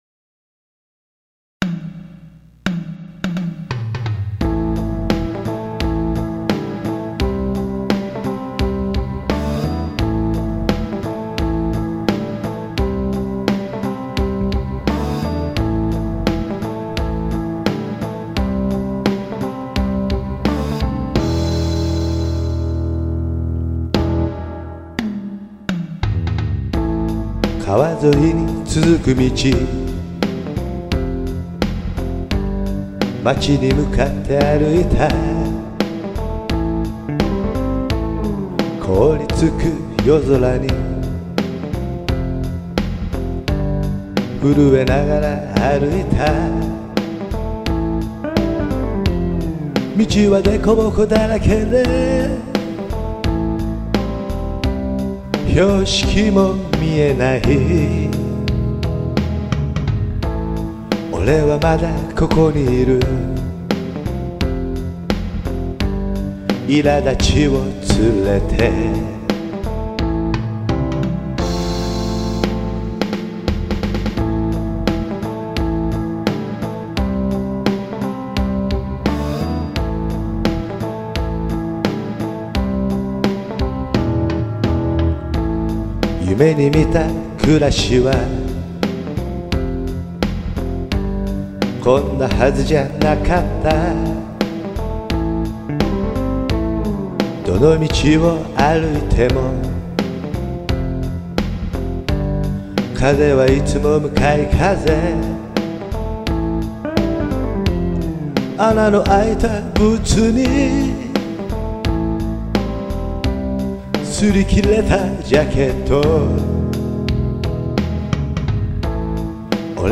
Rock (medium tempo)